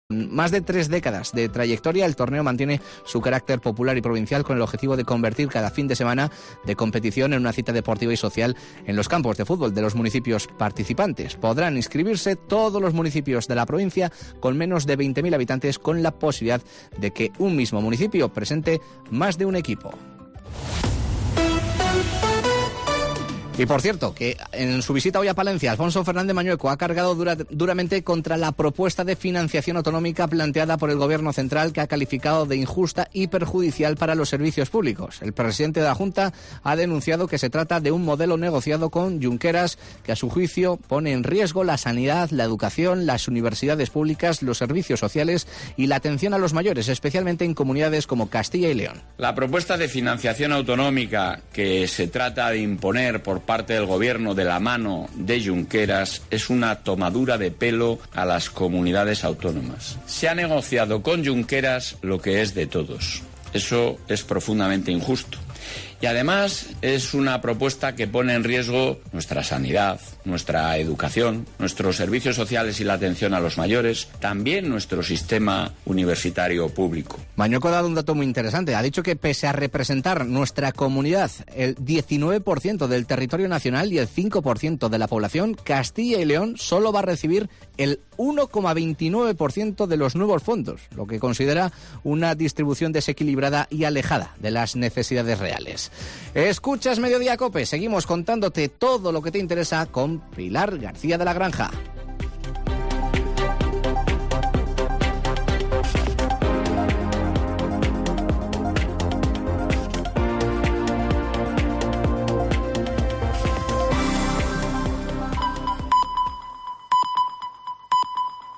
El presidente de la Junta de Castilla y León denuncia desde Palencia un modelo "negociado con Junqueras" que perjudica a la sanidad y la educación
El presidente de la Junta, Alfonso Fernández Mañueco, ha cargado duramente en su visita de hoy a Palencia contra la propuesta de financiación autonómica planteada por el Gobierno central, que ha calificado de injusta y muy perjudicial para los servicios públicos de la comunidad.